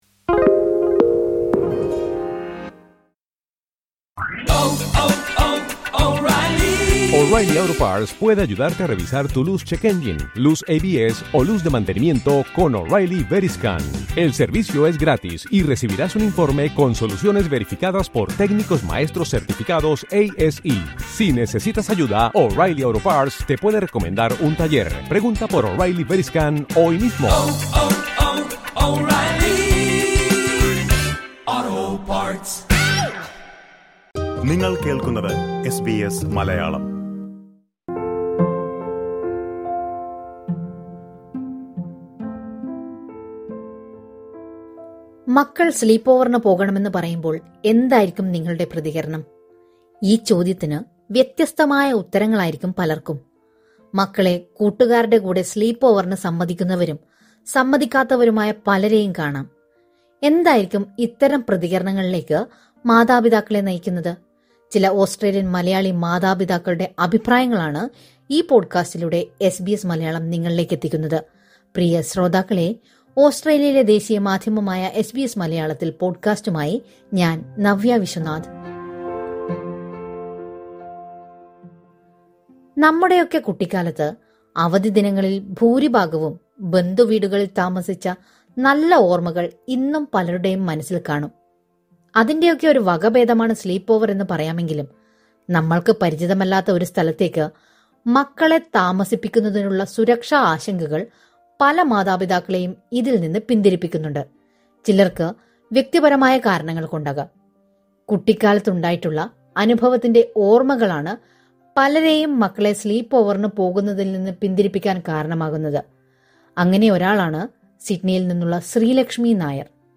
കുട്ടികൾക്കിടയിലെ സൗഹൃദ ബന്ധങ്ങൾ ദൃഢമാക്കാനുള്ള വഴിയായാണ് പലരും സ്ലീപ് ഓവർ സംസ്കാരത്തെ കാണുന്നത്. എന്നാൽ പല മാതാപിതാക്കൾക്കും കുട്ടികളുടെ സുരക്ഷാ കാര്യത്തിൽ ആശങ്ക ഉളവാക്കുന്ന ഒരു കാര്യം കൂടിയാണിത്. സ്ലീപ് ഓവറിനെ കുറിച്ചുള്ള ചില മലയാളി മാതാപിതാക്കളുടെ അഭിപ്രായങ്ങൾ കേൾക്കാം...